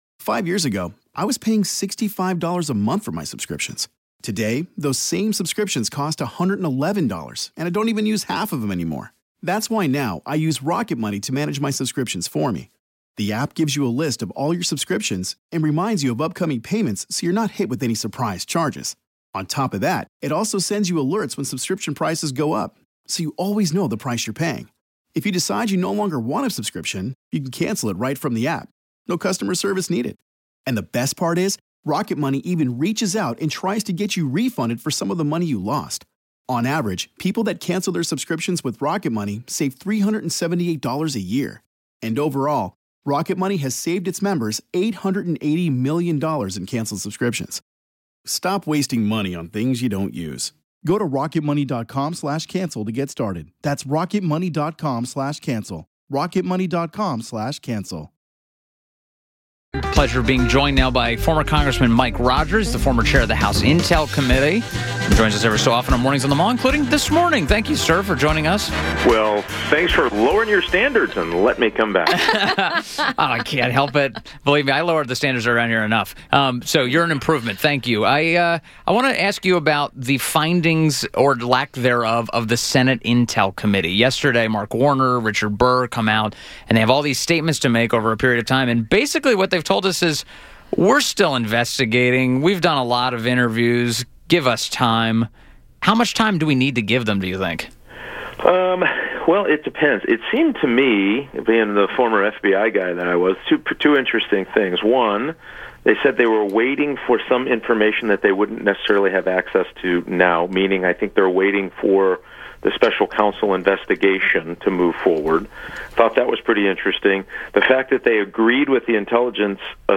WMAL Interview - REP. MIKE ROGERS - 10.05.17
INTERVIEW — REP. MIKE ROGERS – former Michigan congressman, former head of the House Intelligence Committee AND host of the CNN show “Declassified”